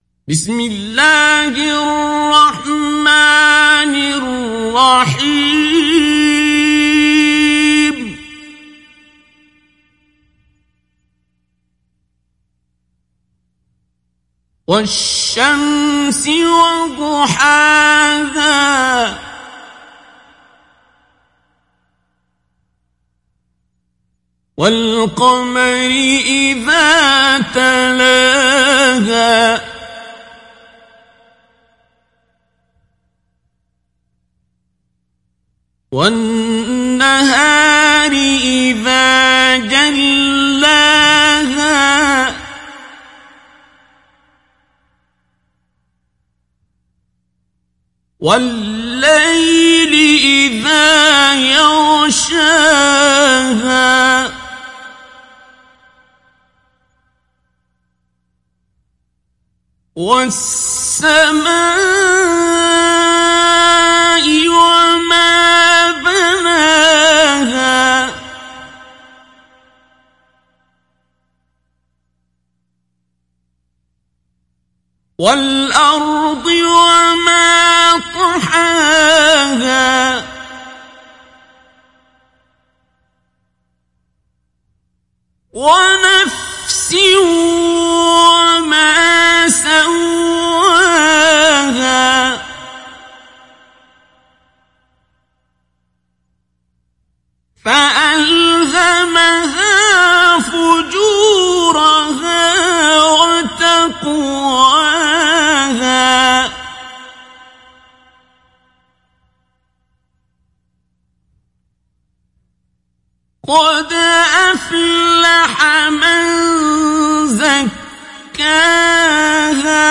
Surah Ash Shams Download mp3 Abdul Basit Abd Alsamad Mujawwad Riwayat Hafs from Asim, Download Quran and listen mp3 full direct links
Download Surah Ash Shams Abdul Basit Abd Alsamad Mujawwad